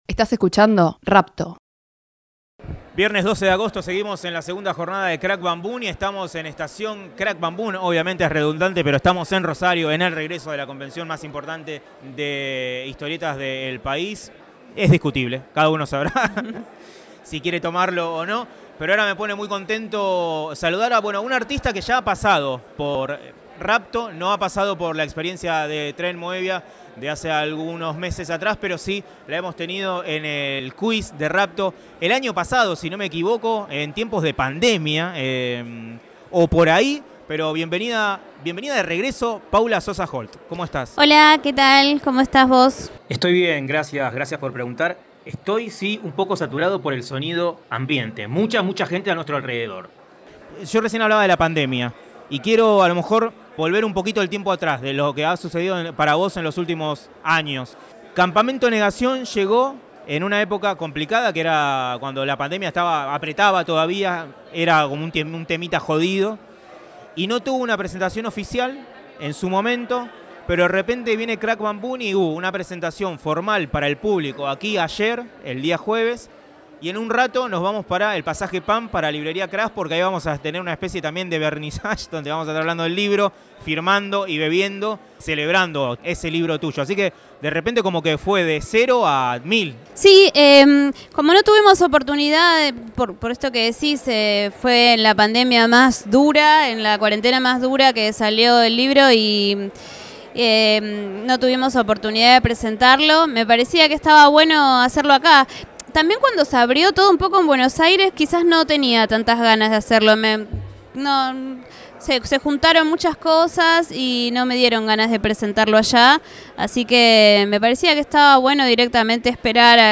La serie se grabó en el subsuelo del Galpón 11, en la comodidad del living de Espacio Moebius.